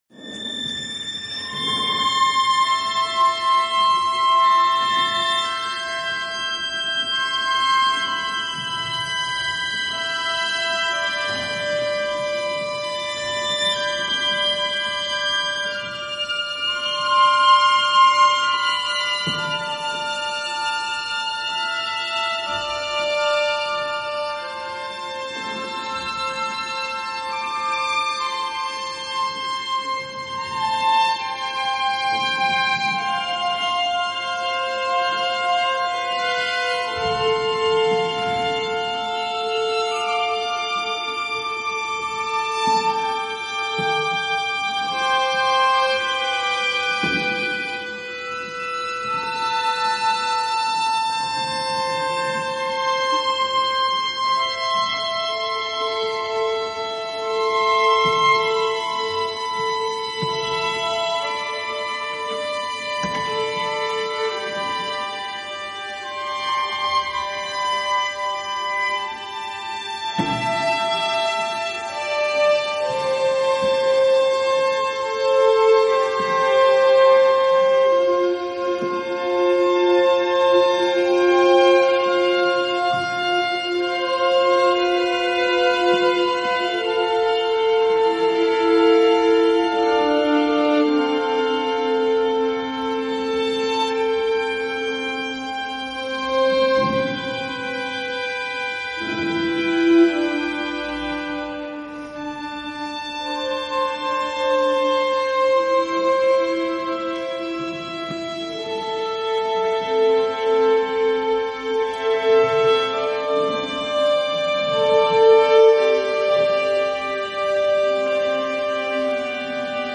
string ensemble
haunting